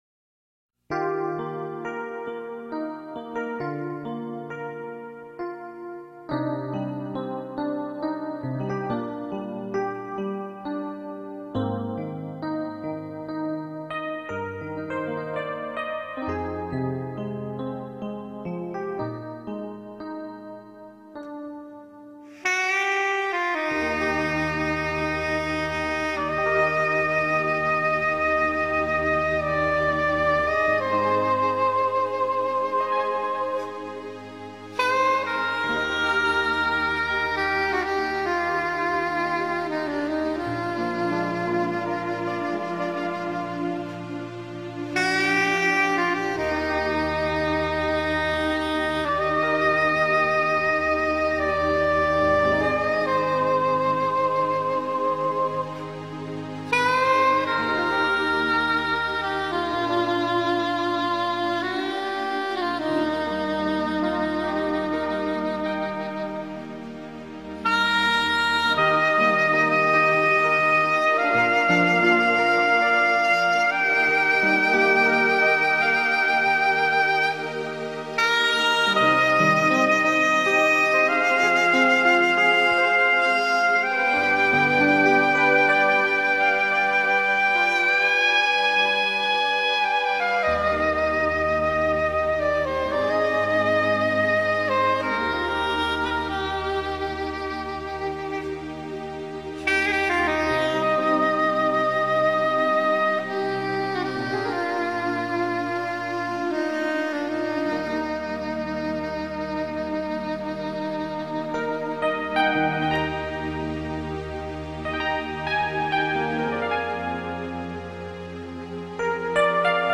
ａｎｎｉｅ-ｌａｕｒｉｅ聖歌５８２番.mp3